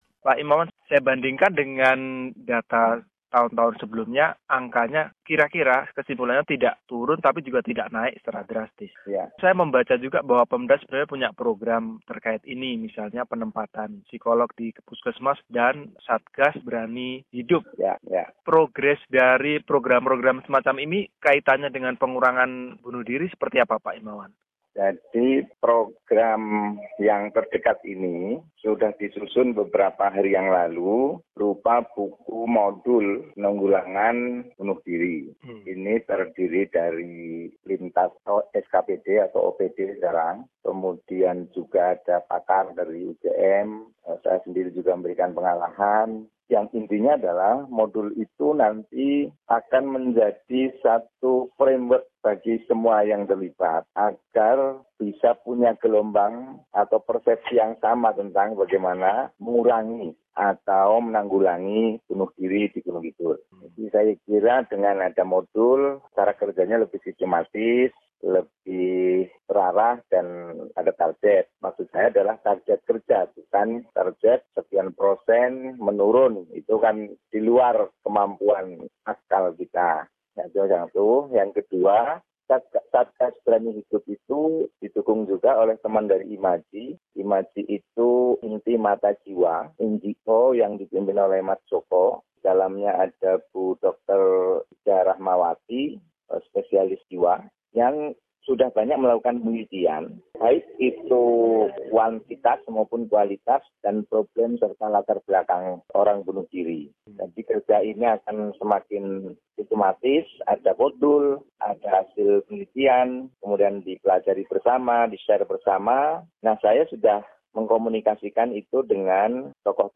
Wakil Bupati Kabupaten Gunung Kidul, Immawan Wahyudi Source: Supplied